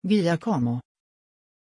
Pronuncia di Giacomo
pronunciation-giacomo-sv.mp3